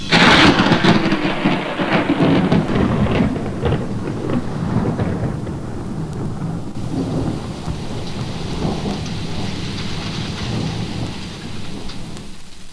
Storm Sound Effect
Download a high-quality storm sound effect.
storm.mp3